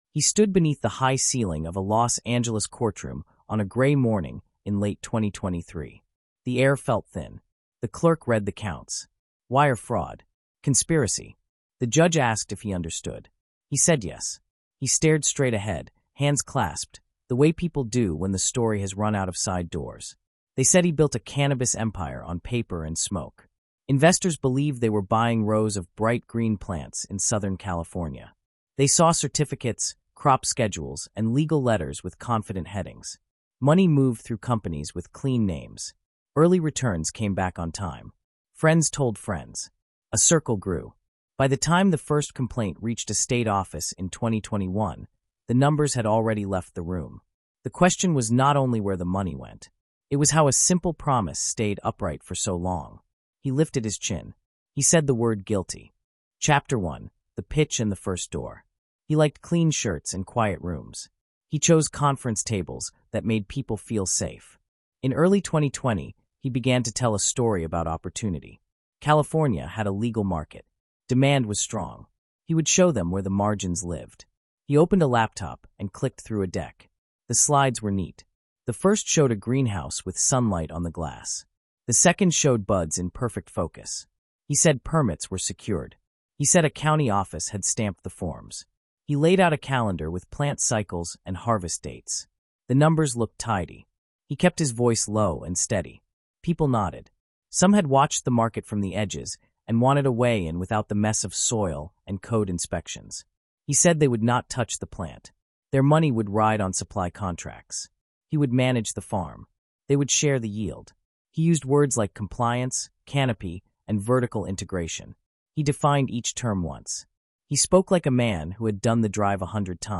The Circle Tightens is a gripping, human-centered true crime dramatization inspired by the real California fraud case in which a smooth-talking con artist built a fake cannabis empire that defrauded investors of over eighteen million dollars. Told in the reflective, steady tone of a Shawshank Redemption-style narrative, the story unfolds across three haunting chapters—rising from ambition and illusion to collapse and reckoning.